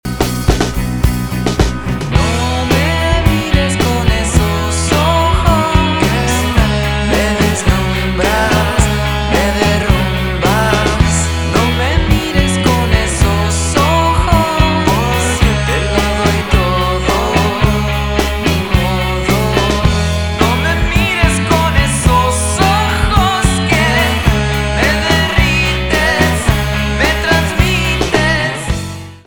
альтернатива
рок , барабаны , гитара
спокойные